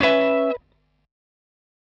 Dbm7_18.wav